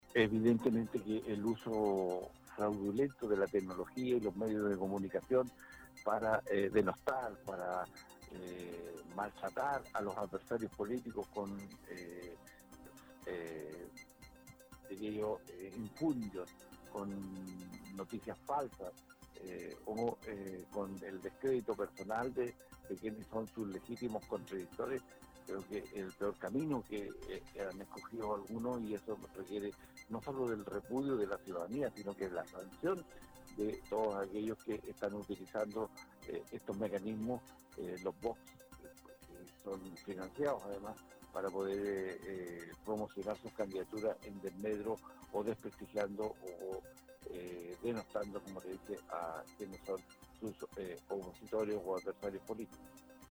En entrevista con Radio UdeC, Saavedra advirtió que, sin sanciones claras, la obligatoriedad del sufragio queda en entredicho.